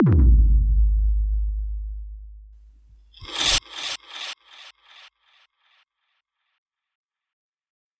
描述：断裂，填充效果。修改过的踢鼓和我用尺子划过桌子边缘发出的声音。这一次，尺子的声音来得稍晚一些。另外，还带有延迟效果。